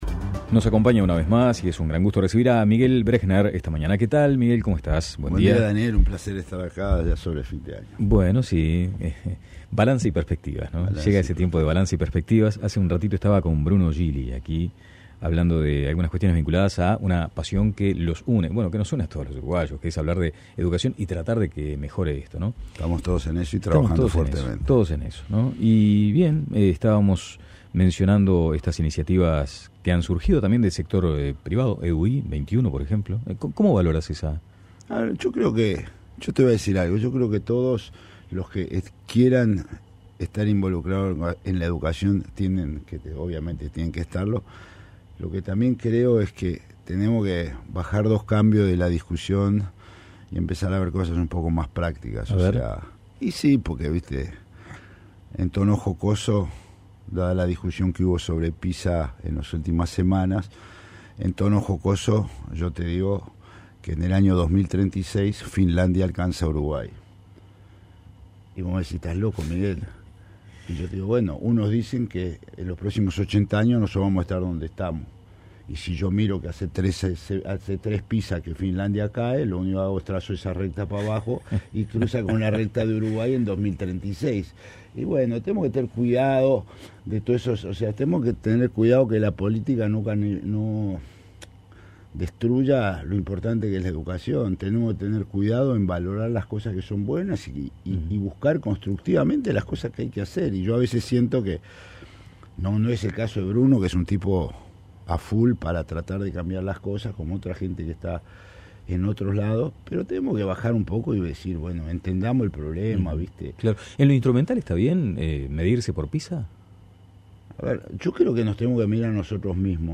Miguel Brechner, presidente del Plan Ceibal, visitó La Mañana de El Espectador para discutir sobre el sistema educativo uruguayo y también tratar temas como el Plan Ibirapitá.
Escuche la entrevista en La Mañana